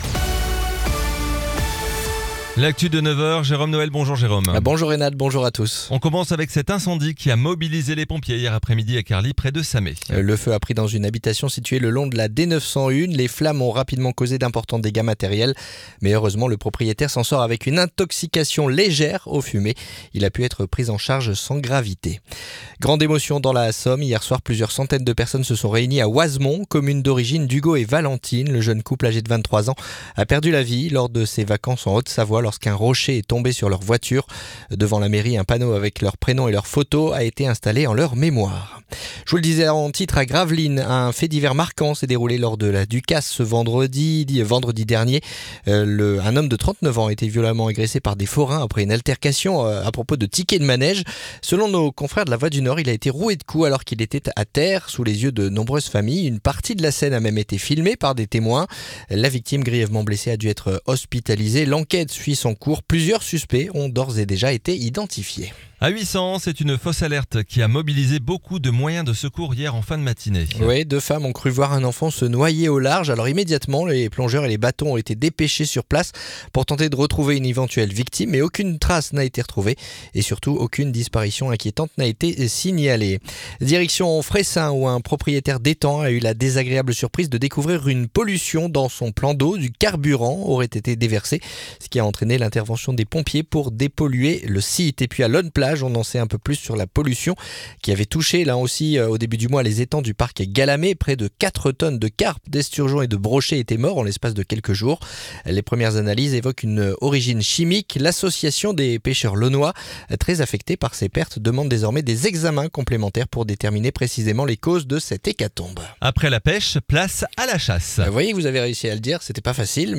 Le journal du vendredi 22 août